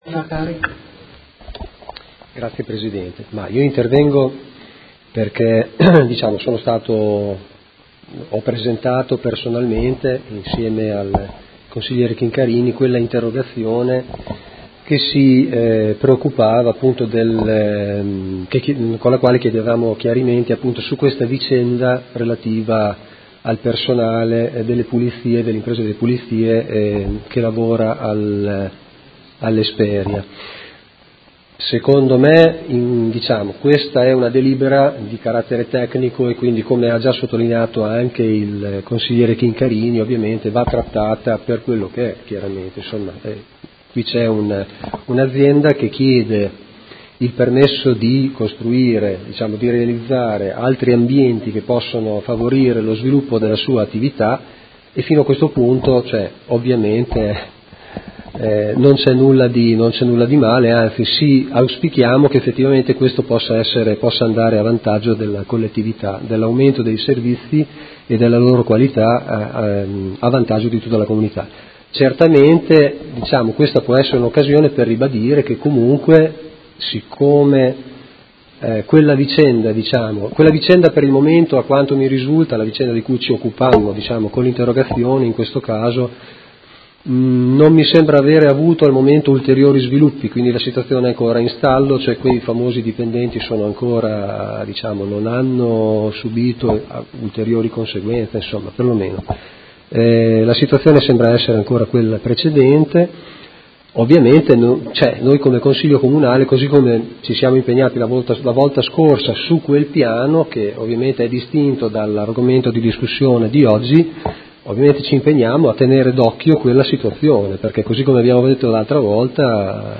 Seduta del 13/07/2017 Dibattito.